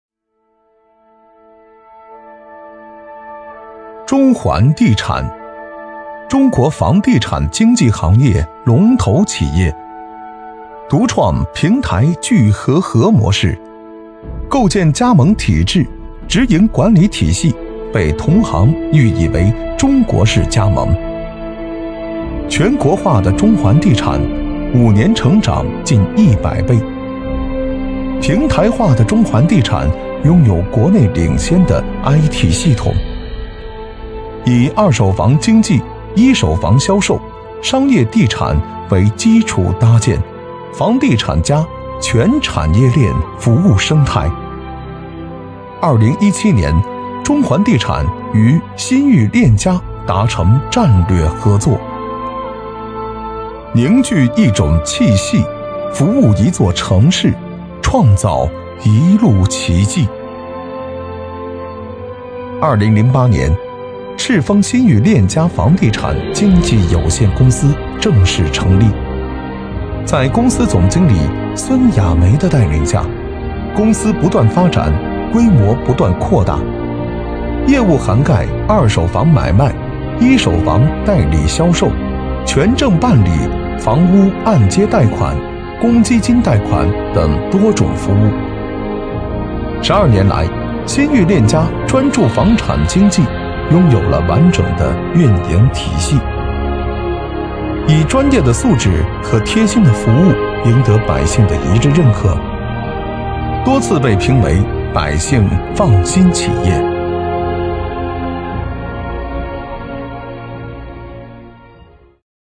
【男50号宣传片】房地产
【男50号宣传片】房地产.mp3